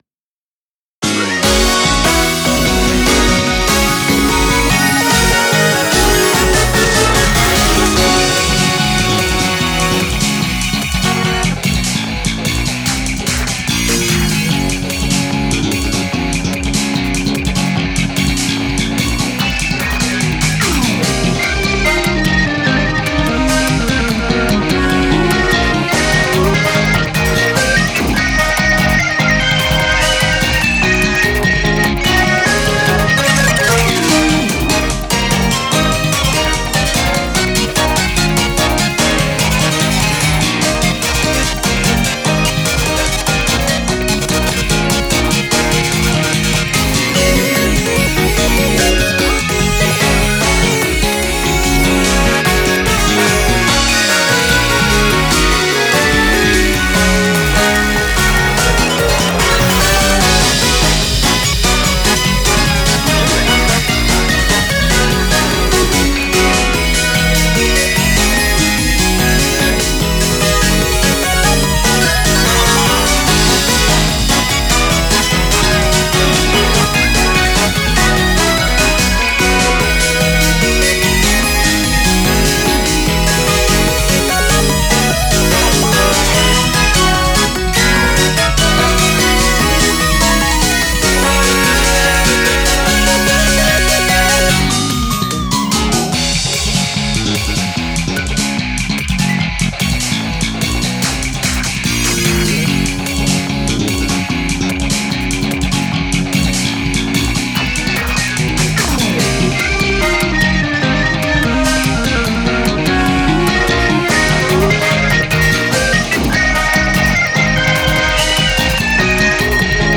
かっこいい雰囲気の曲です。
タグ かっこいい